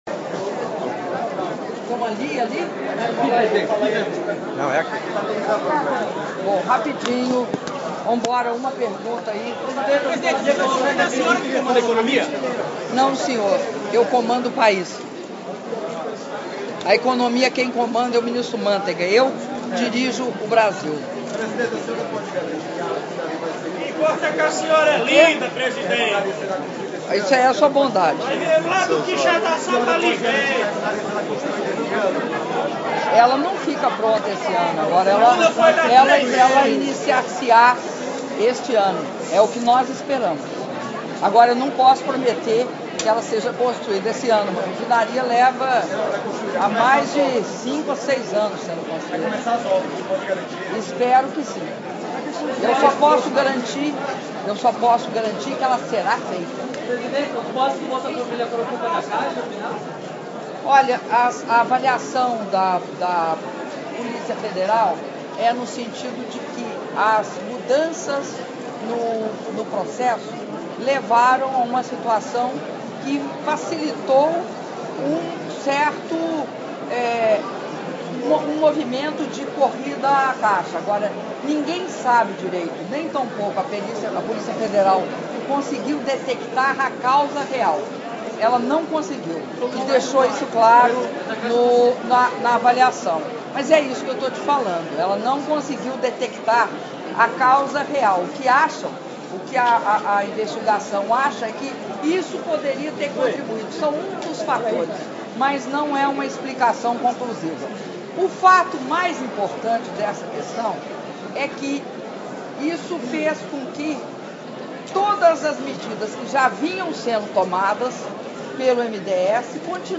Entrevista coletiva concedida pela Presidenta da República, Dilma Rousseff, após cerimônia de formatura de 2.400 alunos do Pronatec/BSM
Fortaleza-CE, 18 de julho de 2013